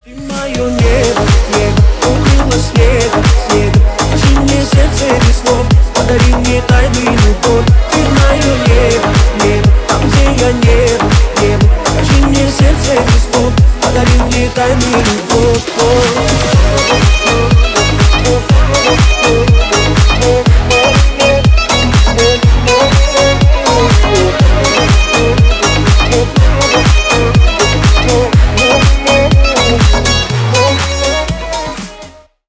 Ремикс
Танцевальные
кавказские